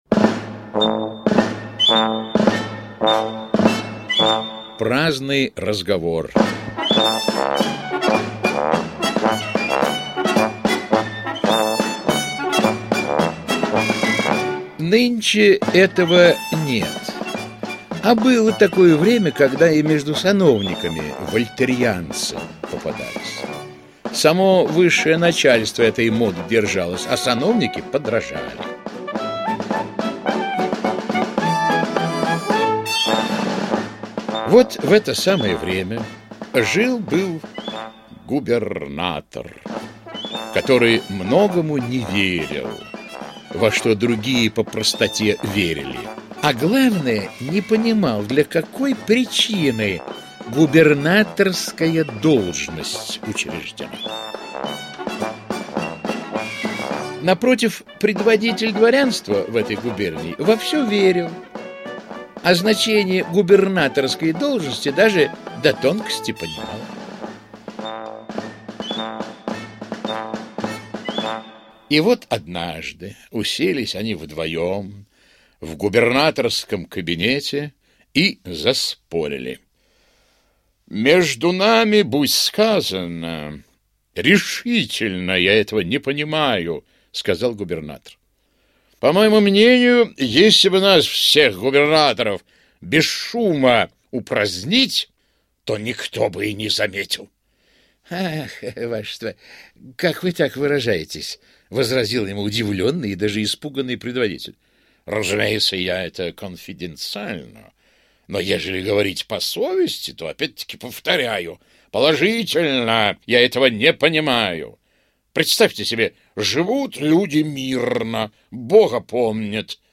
Праздный разговор - аудиосказка Михаила Салтыкова-Щедрина - слушать онлайн